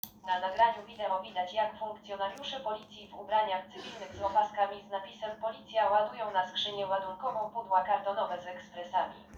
Nagranie audio Audiodeskrypcja_filmu_Policjanci_z_Ryk_odzyskali_ekspresy_do_kawy_warte_blisko_300_000_zl.m4a